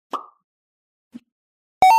Free UI/UX sound effect: Coin Collect.
Coin Collect
# coin # collect # game # 8bit About this sound Coin Collect is a free ui/ux sound effect available for download in MP3 format.
312_coin_collect.mp3